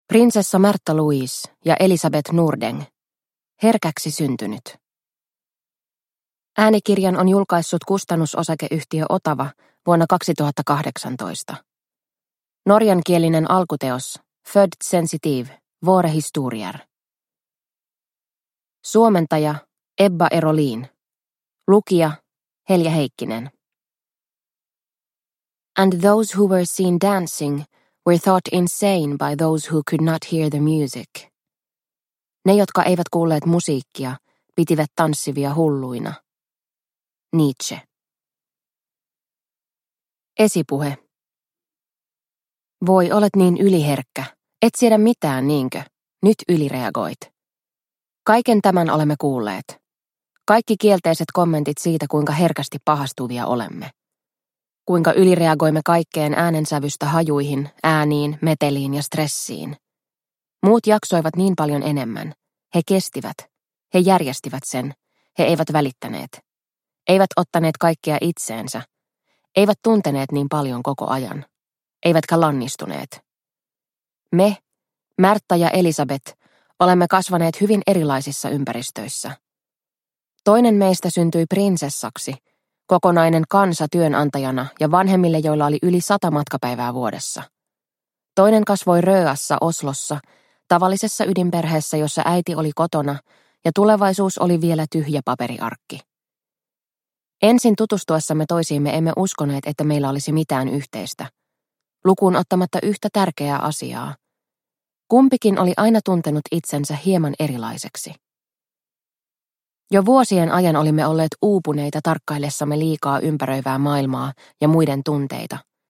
Herkäksi syntynyt – Ljudbok – Laddas ner